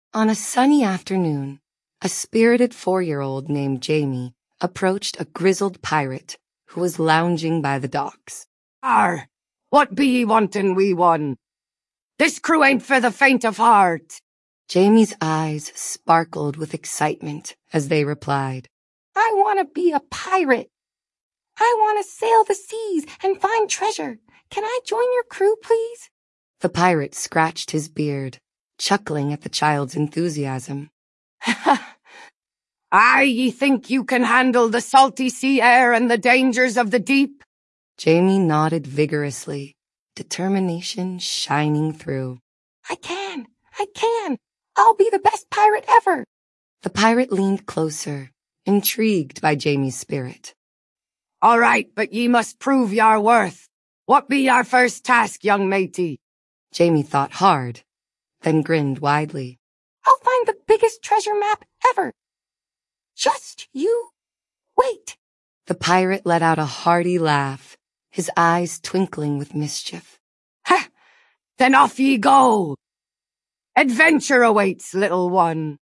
Sprachmodell für Copilot und Podcasts
MAI-Voice-1 ist das erste Sprachsystem, das vollständig in Microsofts eigener Entwicklungsabteilung entstanden ist. Es soll gesprochene Inhalte möglichst natürlich und ausdrucksstark wiedergeben.
Die Ergebnisse klingen dann etwa so: